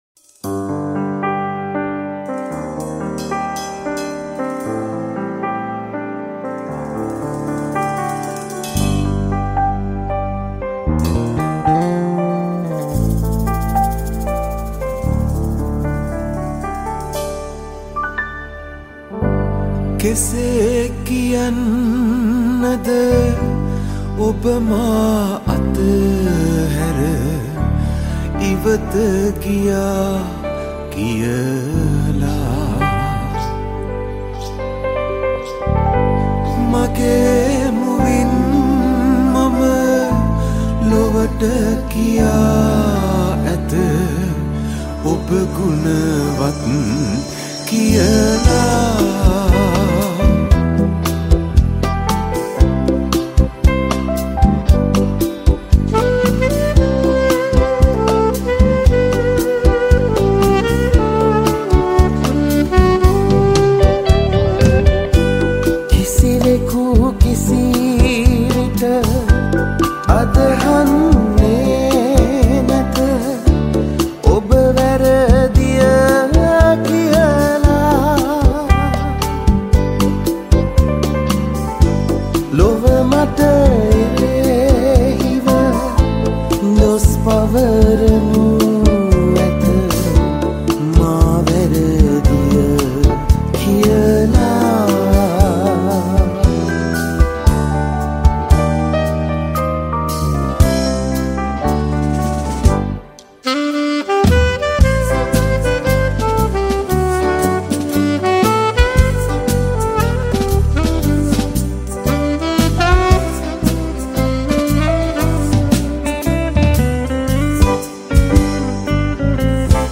High quality Sri Lankan remix MP3 (6.4).